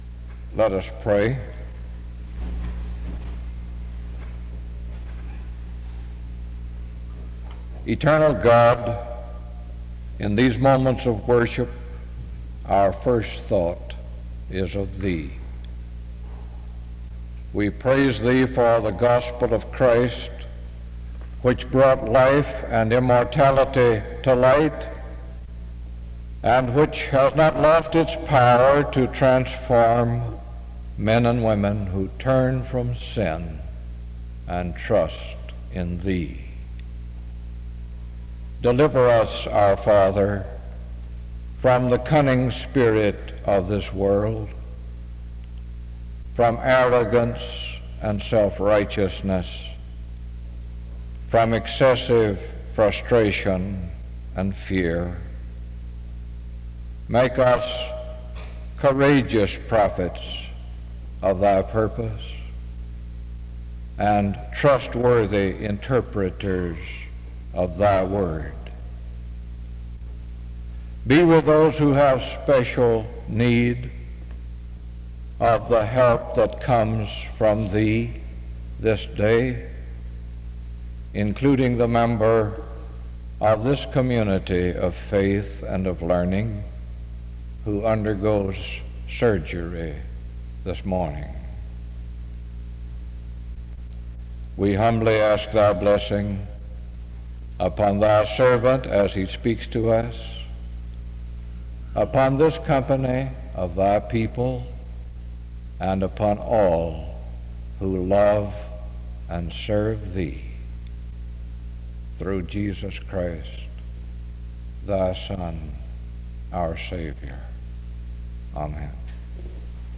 SEBTS Chapel - Robert Blackburn March 29, 1973
Robert Blackburn was a bishop in the United Methodist Church.
SEBTS Chapel and Special Event Recordings